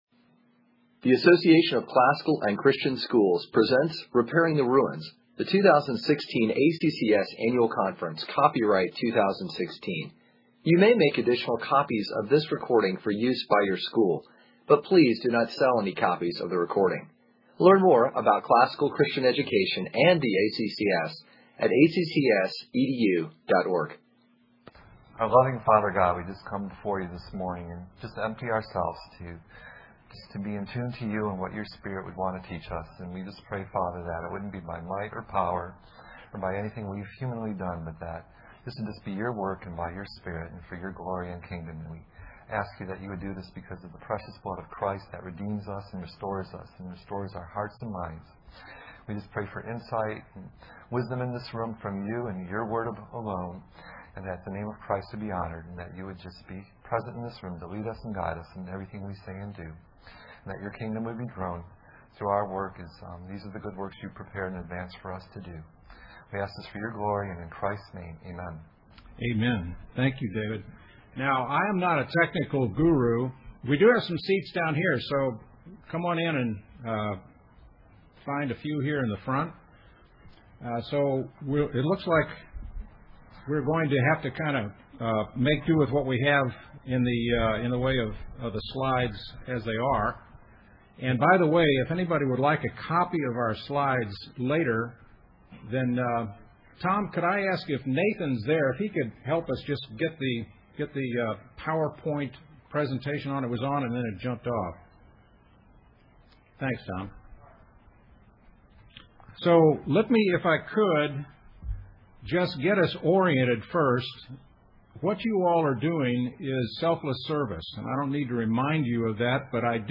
2016 Foundations Talk | 46:29:00 | Leadership & Strategic
Additional Materials The Association of Classical & Christian Schools presents Repairing the Ruins, the ACCS annual conference, copyright ACCS.